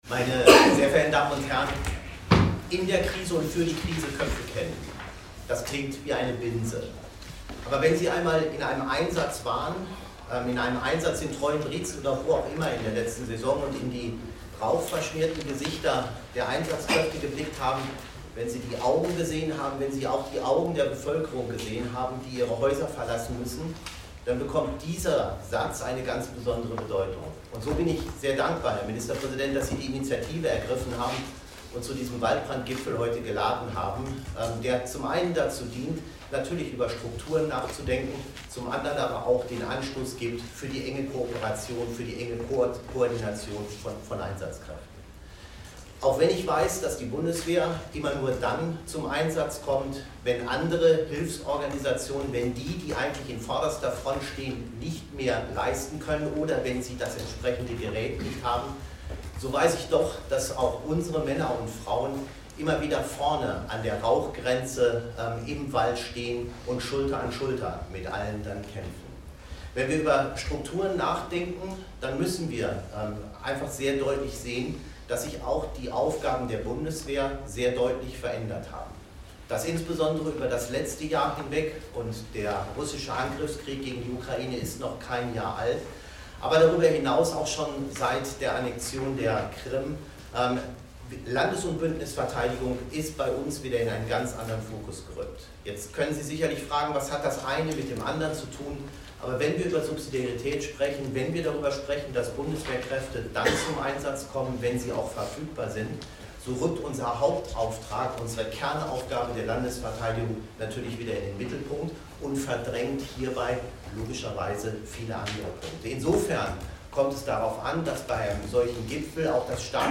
„Wir werden weiterhin bewährt unterstützen und helfen, wo wir können“, betont Generalleutnant Carsten Breuer in seinem Pressestatement.
statement-data.mp3